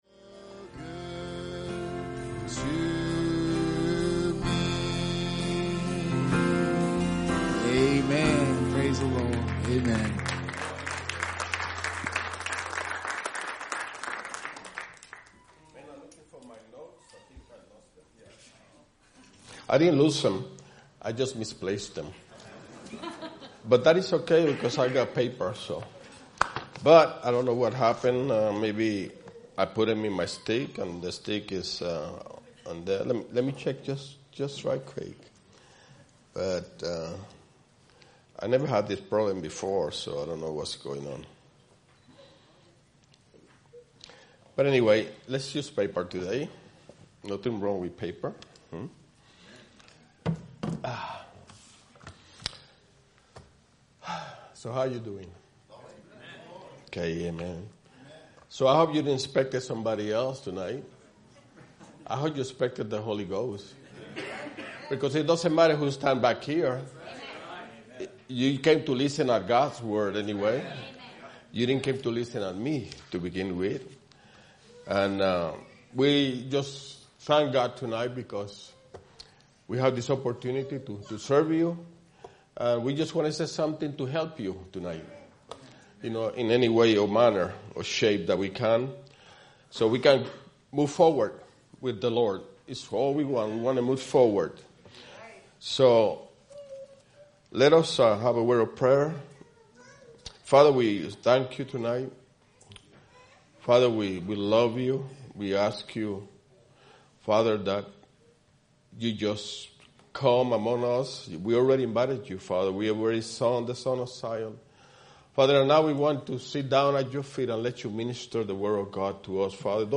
Youth Meeting